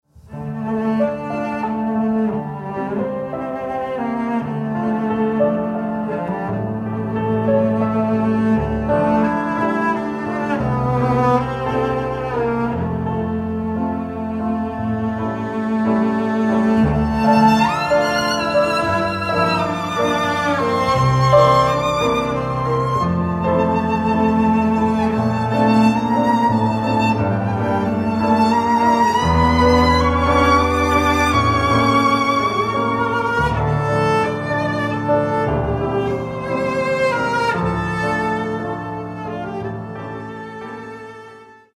Stereo
violin
cello
piano